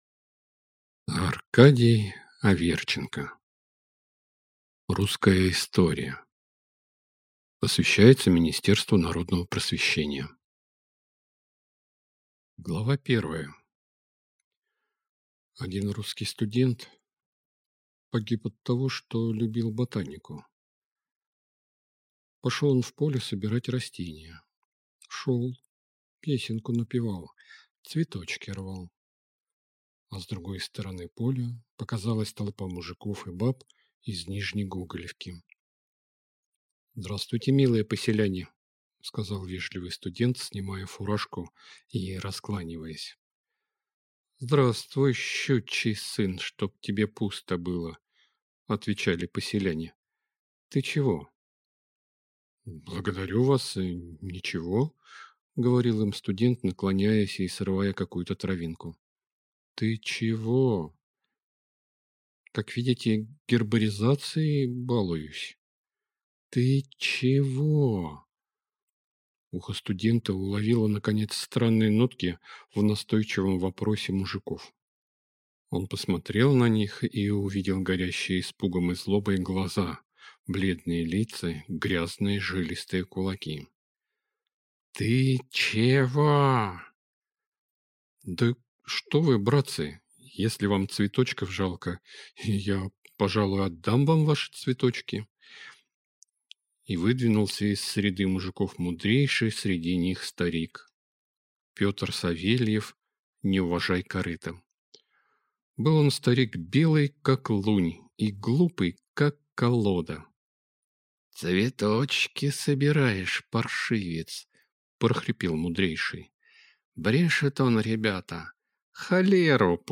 Аудиокнига Русская история | Библиотека аудиокниг